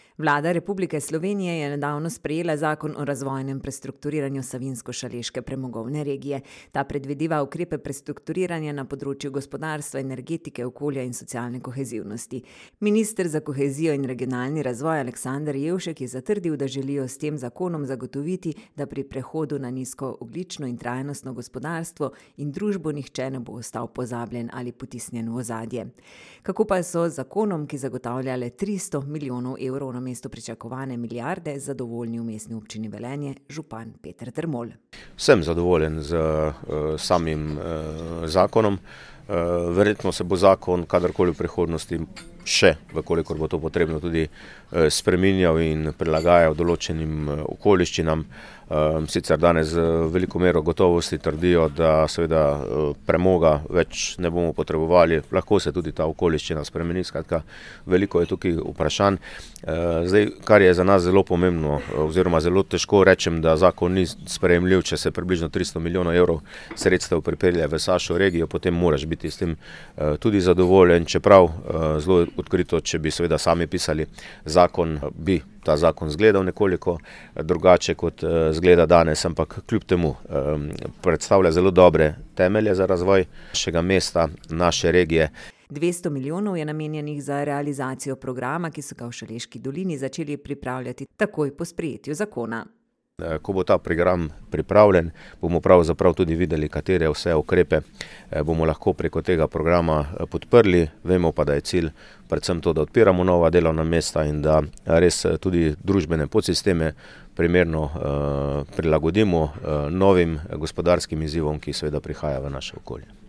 Kako pa so z zakonom, ki zagotavlja le 300 milijonov namesto pričakovane milijarde evrov, zadovoljni v mestni občini Velenje, župan Peter Dermol: